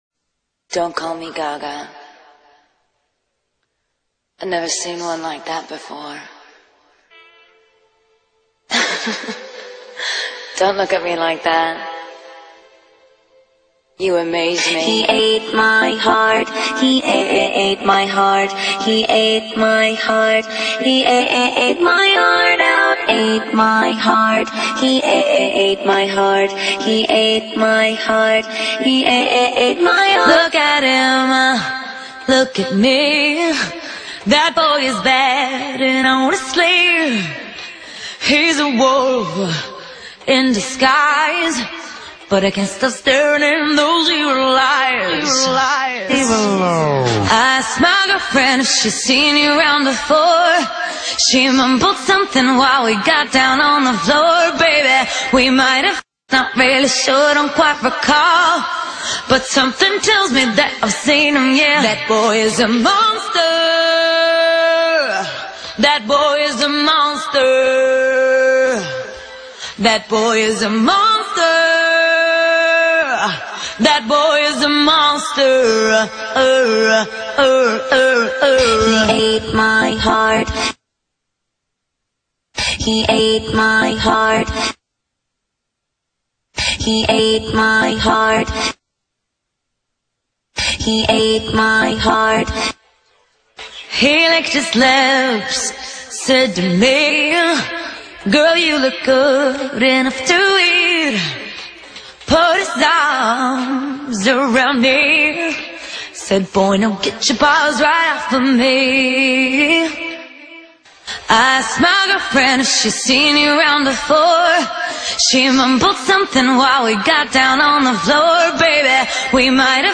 Категория: Скачать Зарубежные акапеллы